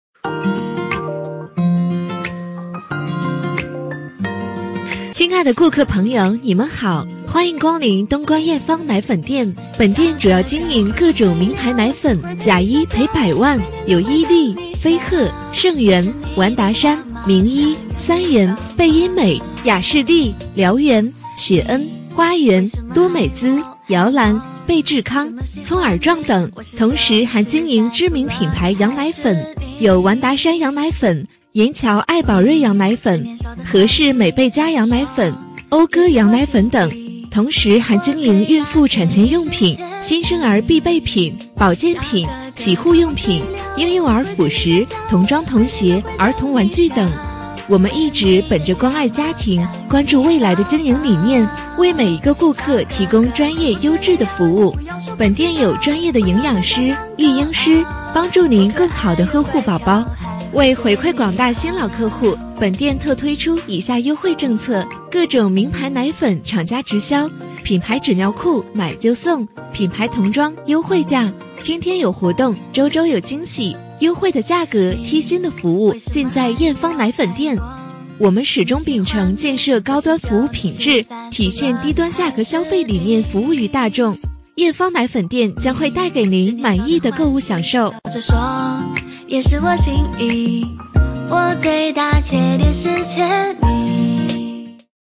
【女57号促销柔和】奶粉店+音乐